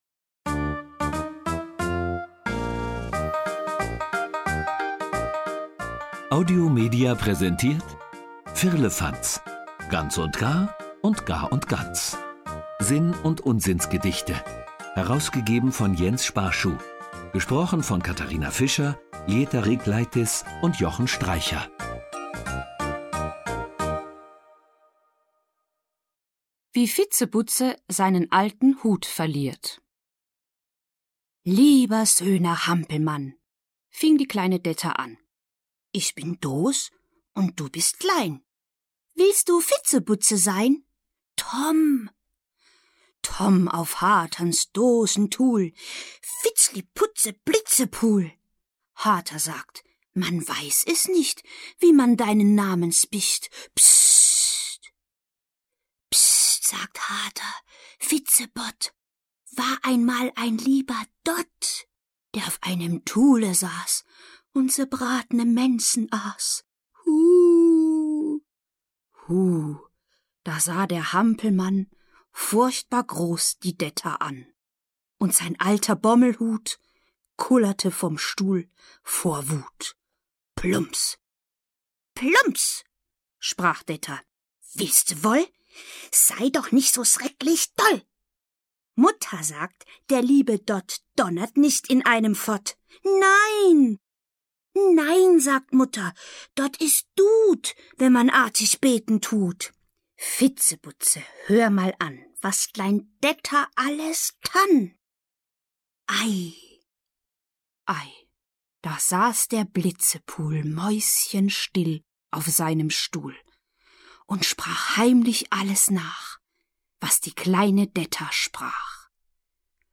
Sinn- und Unsinnsgedichte
Schlagworte Gedichte • Hörbuch; Hörspiel für Kinder/Jugendliche • Reime • Sprachzirkus • Unsinn